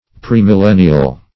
Premillennial \Pre`mil*len"ni*al\, a. Previous to the millennium.